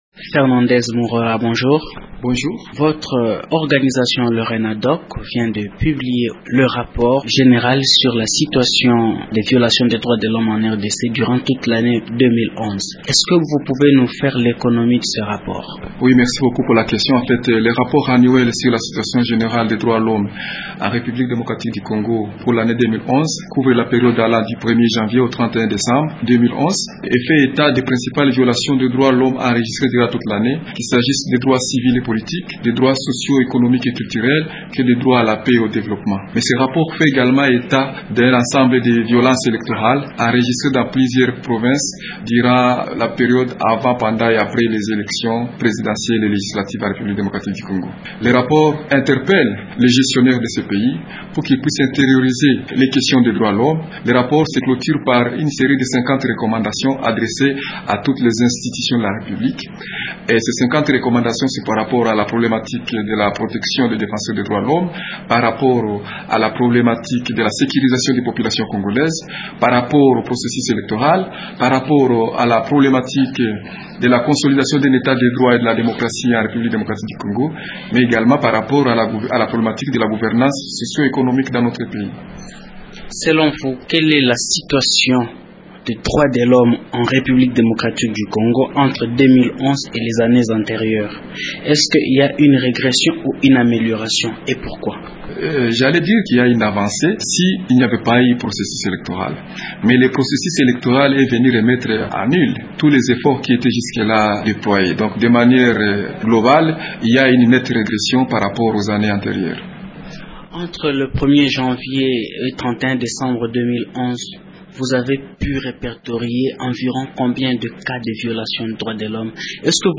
Il est interrogé par